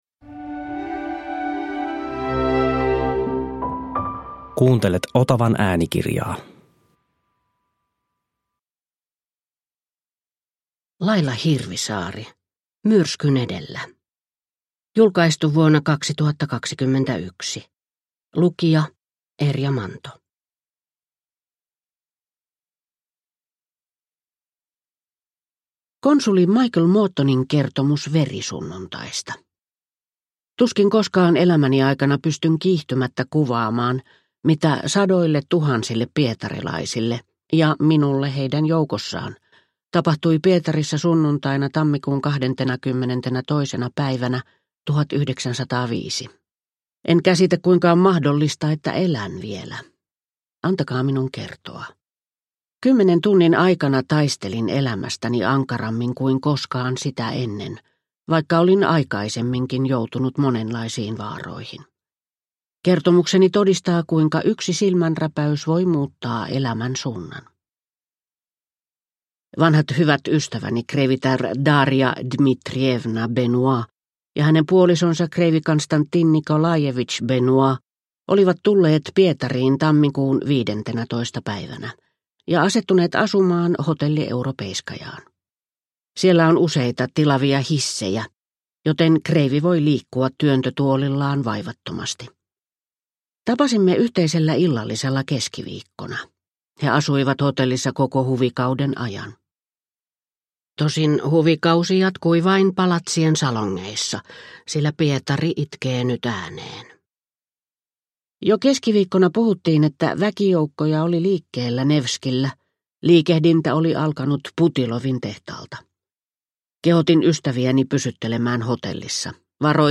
Myrskyn edellä – Ljudbok – Laddas ner